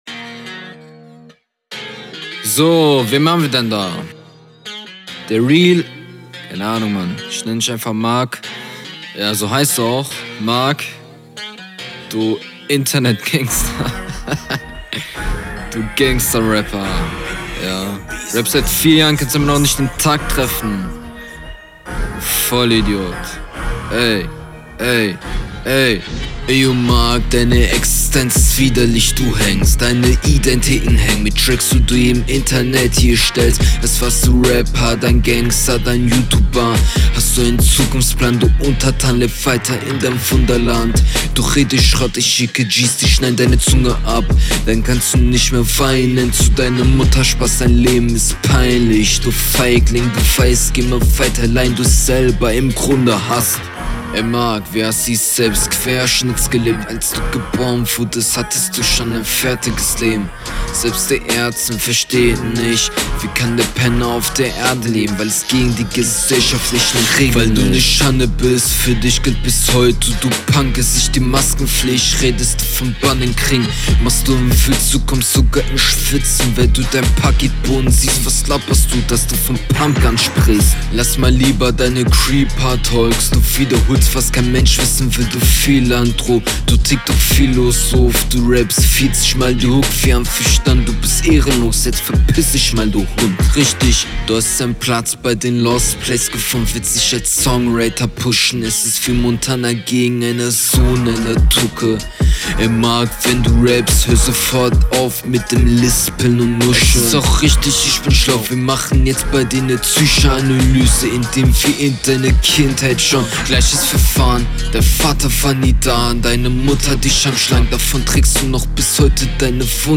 Battle Runden